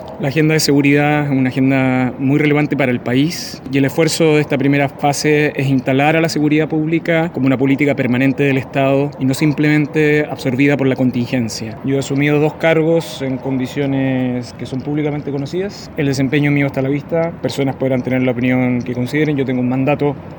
Posterior a ello, antes de abandonar Palacio, aseguró que el sello de su gestión estará en instalar la seguridad pública como una política permanente.